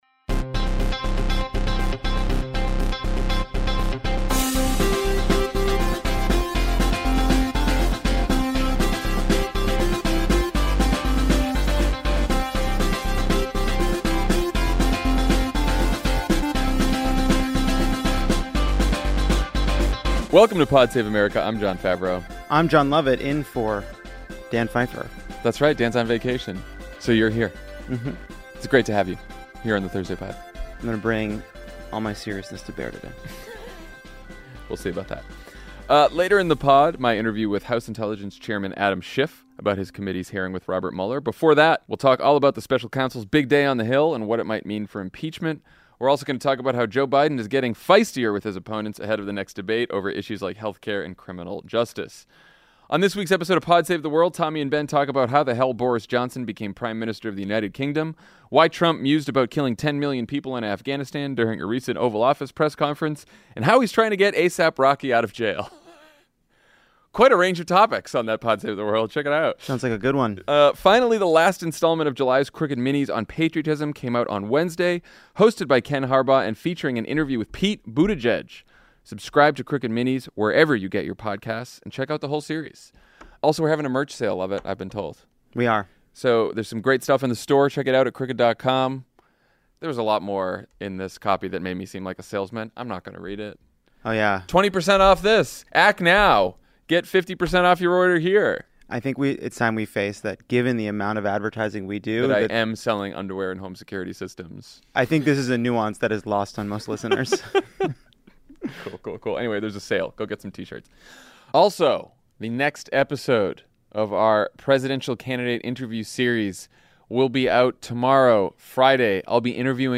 Special Counsel Robert Mueller testifies for six hours before the House Judiciary and Intelligence Committees, Democratic leaders remain hesitant on impeachment, and Joe Biden gets scrappy with his opponents over health care and criminal justice reform. Then House Intelligence Committee Chairman Adam Schiff talks to Jon F. about the Mueller hearing and impeachment.